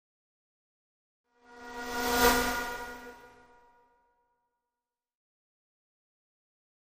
Static Buzz By Thin Buzz, Creepy Pass - Version 5